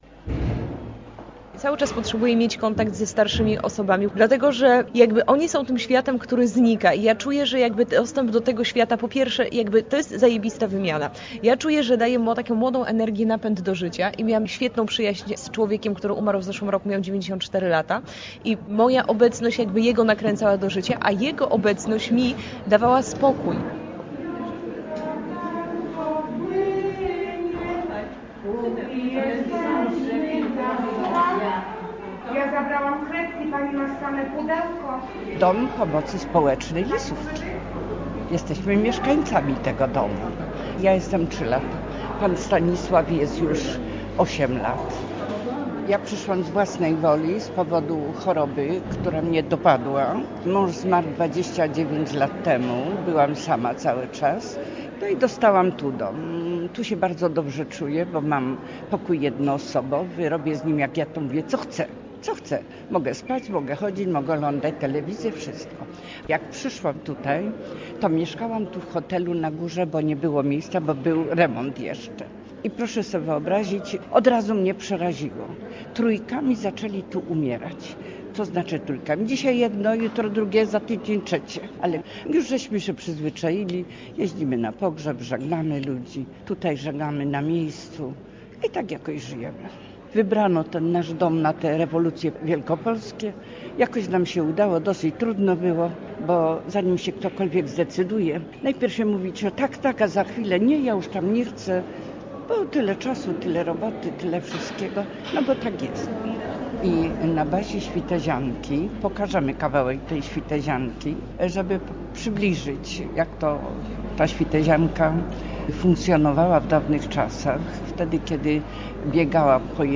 Wymiana energii - reportaż